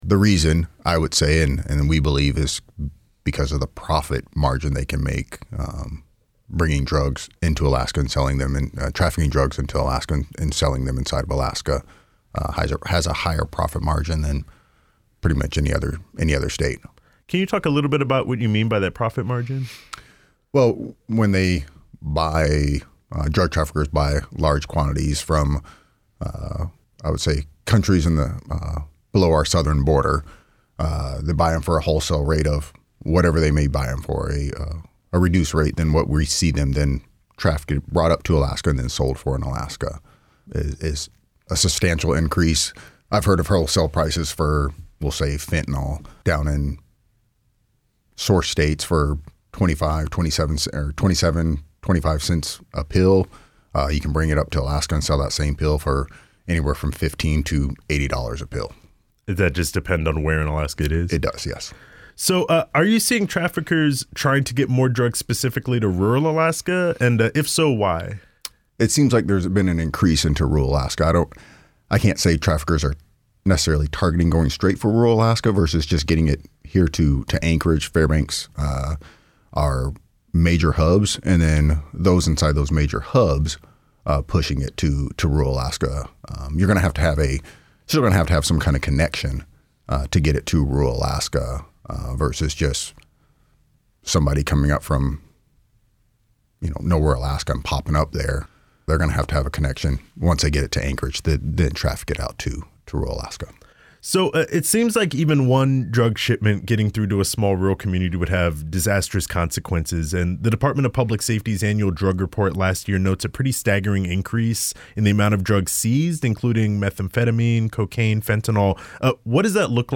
This interview has been lightly edited for clarity.